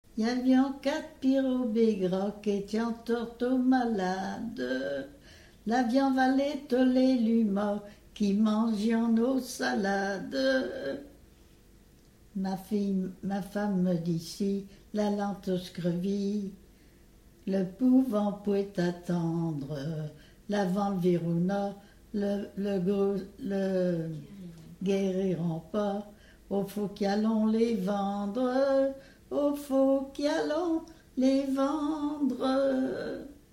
Mouilleron-le-Captif ( Plus d'informations sur Wikipedia ) Vendée
Thème : 2137 - Lettrées patoisantes
Catégorie Pièce musicale inédite